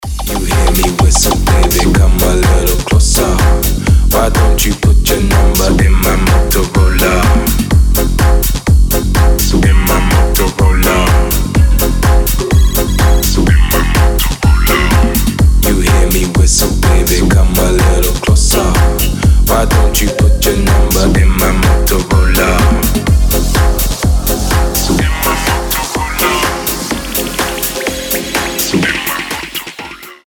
• Качество: 320, Stereo
ритмичные
громкие
забавные
Electronic
EDM
Bass House
house